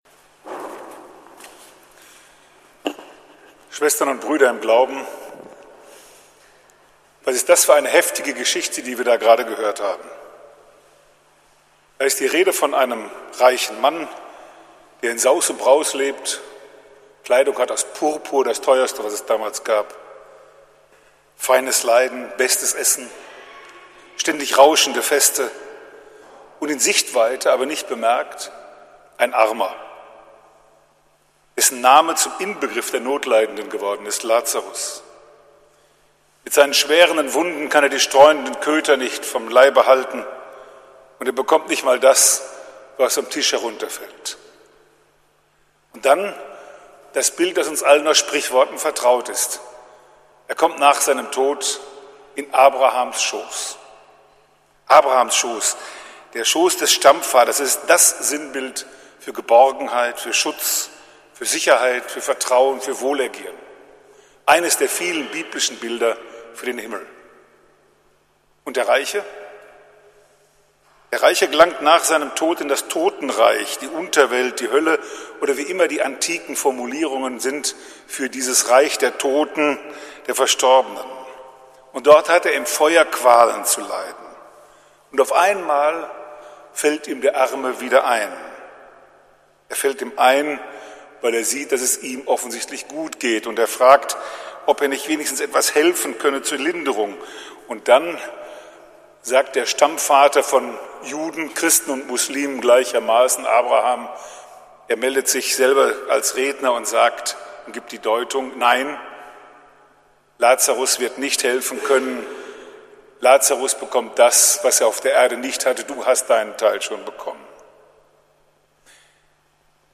Fastenpredigt von Prof. Dr. Dr. Sternberg – St. Nikolaus Münster